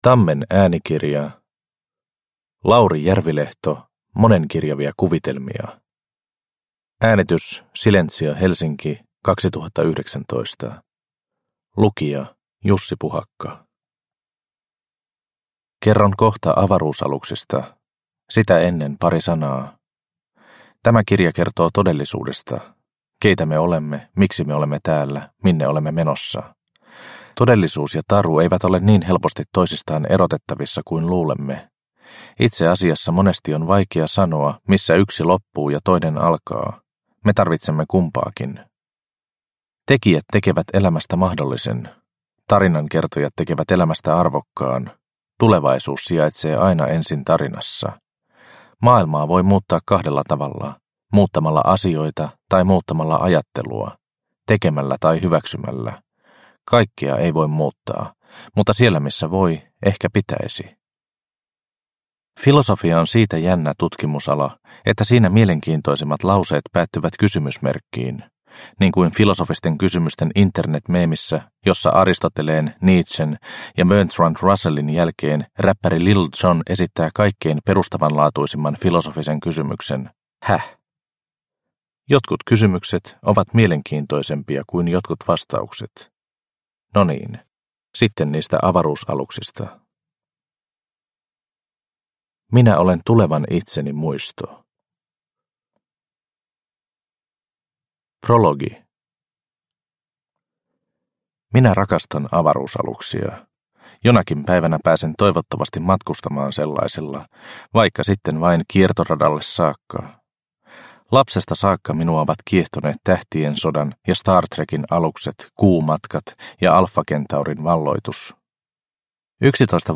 Monenkirjavia kuvitelmia – Ljudbok